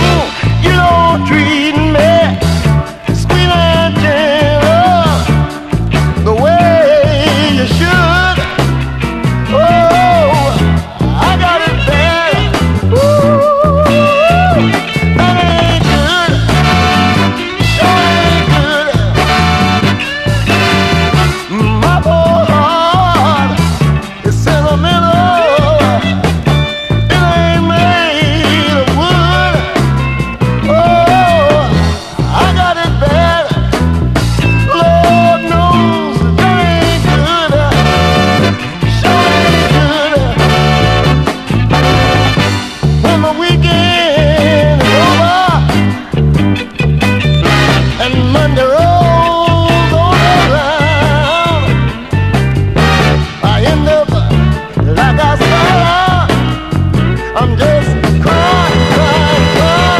WORLD / OTHER / BRAZIL / RARE GROOVE / SOFT ROCK
5作目となる本作も期待を裏切ることの無い、華やかな男女コーラスにホーン・フレーズ、洒脱で軽やかなアレンジが冴え渡る傑作！